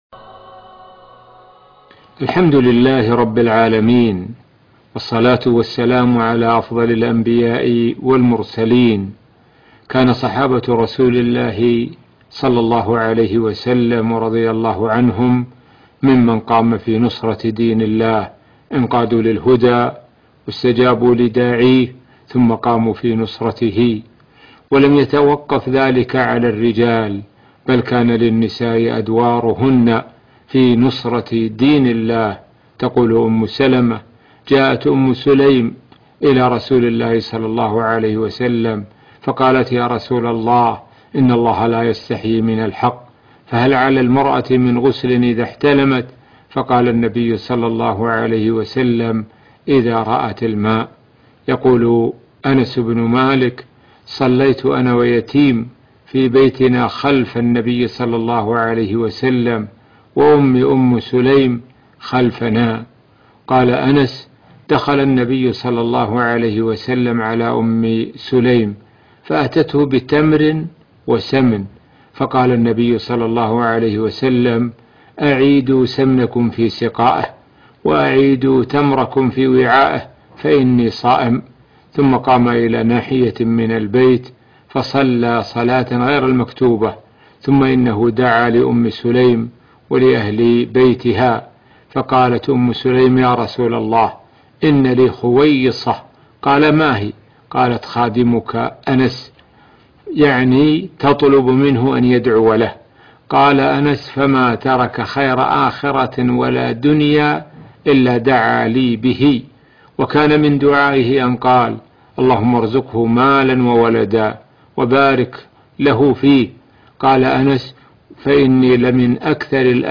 عنوان المادة الأيام الخالية - الشيخ سعد الشثري يروي قصصاً عن الصحابية الجليلة أم سليم